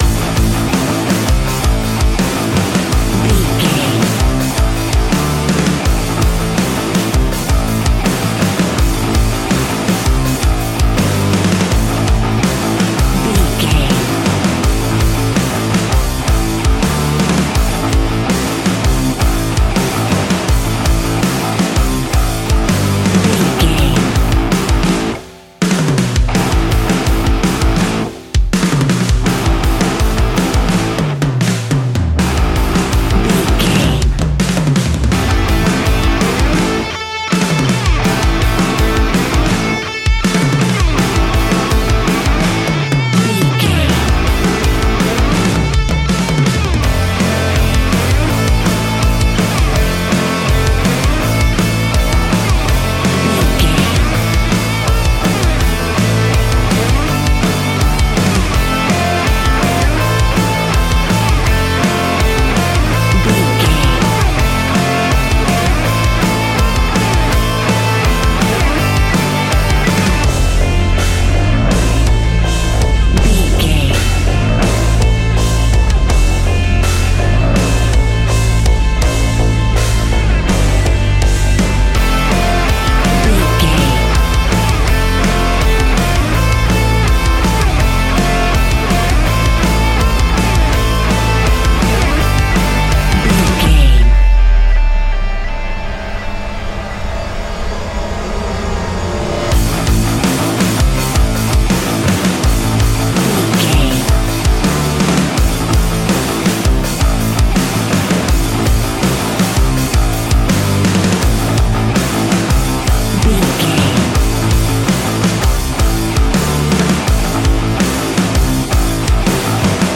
Ionian/Major
F♯
hard rock
heavy metal